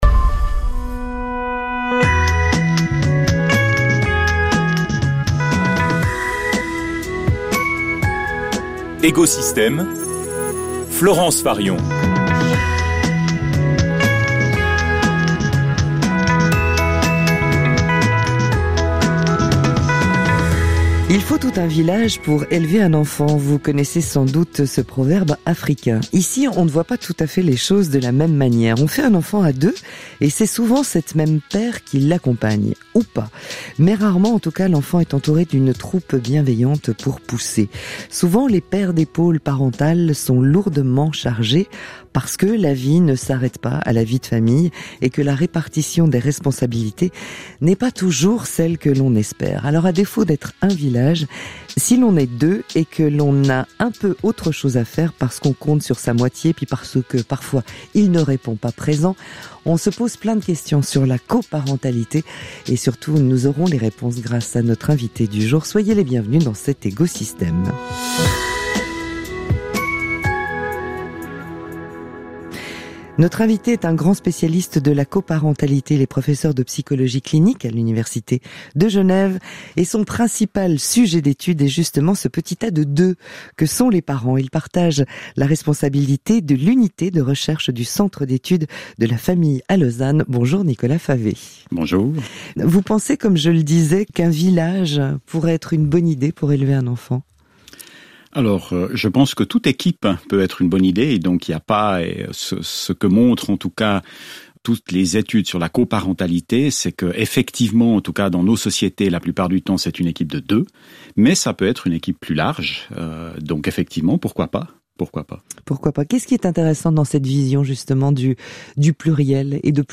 un entretien